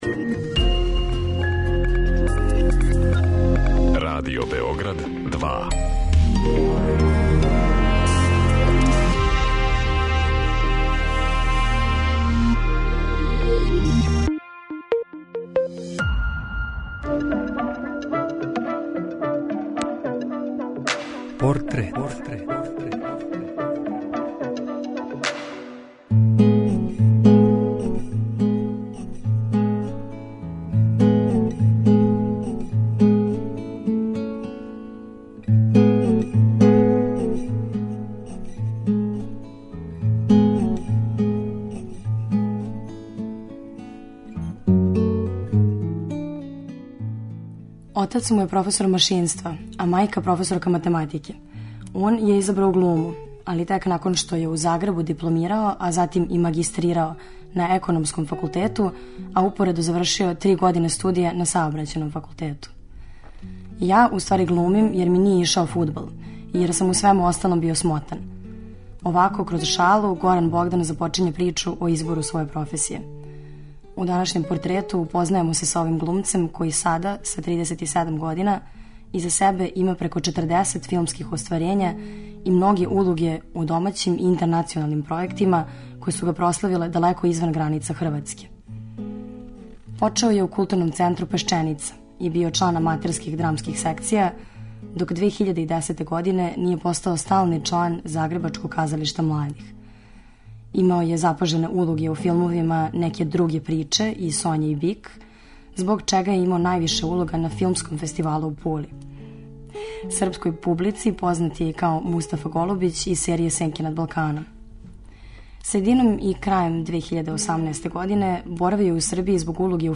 суптилним радиофонским ткањем сачињеним од: интервјуа, изјава, анкета и документраног материјала.